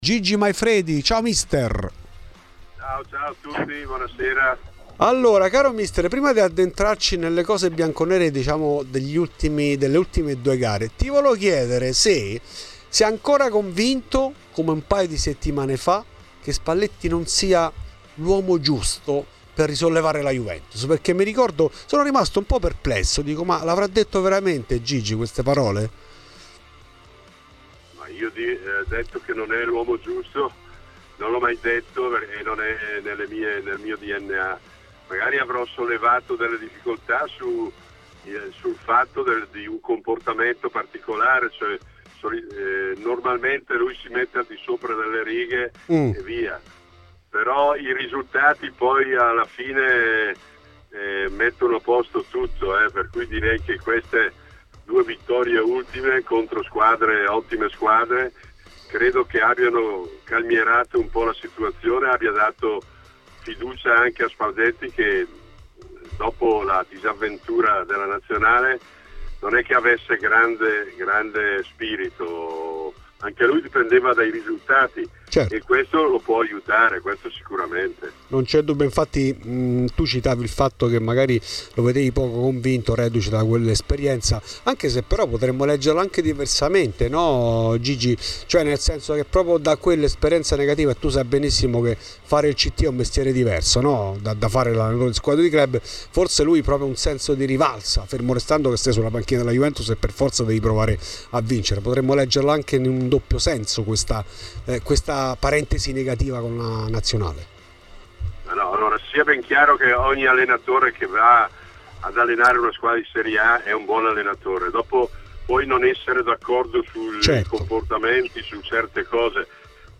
Ospite di Radio Bianconera, durante Fuori di Juve, mister Gigi Maifredi è tornato sugli ultimi successi della squadra di Luciano Spalletti: "Non ho mai detto che Spalletti non è l'uomo giusto per risollevare la Juve.